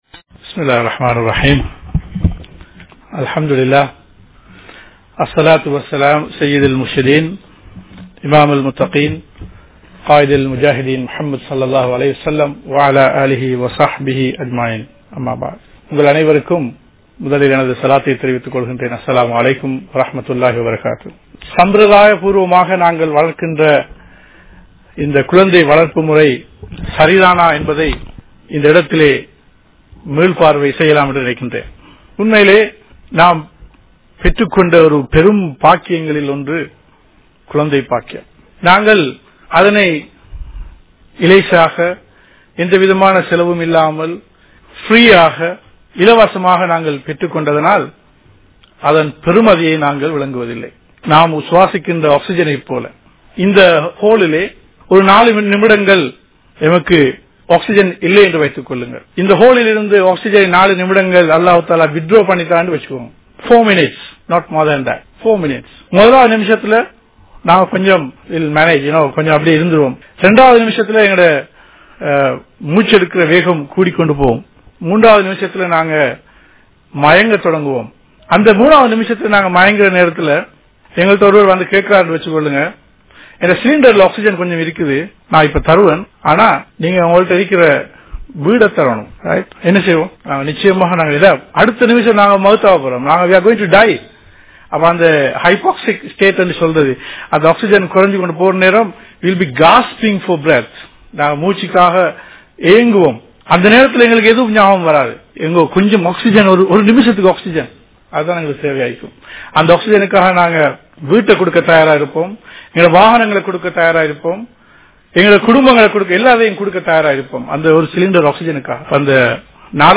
How to Handle a Child? | Audio Bayans | All Ceylon Muslim Youth Community | Addalaichenai